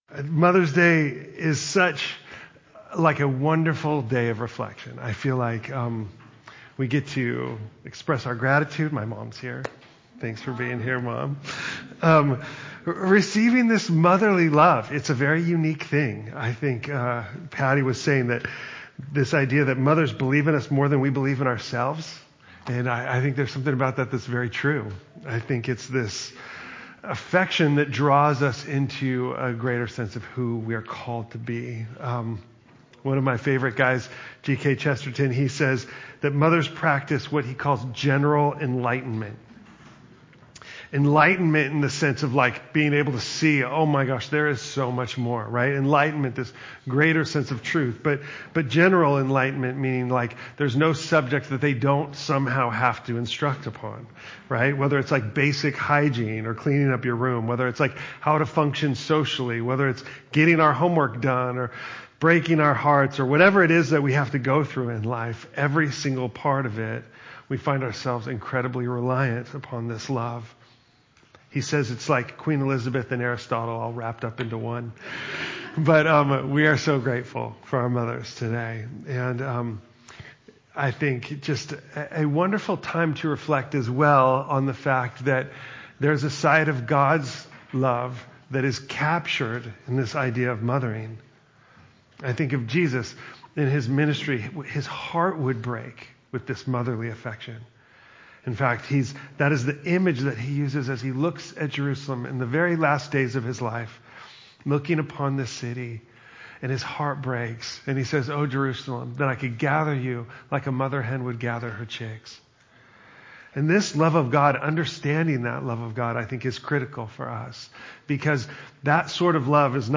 unveiling God’s glory in surrender” for our Ordinary Time sermon series.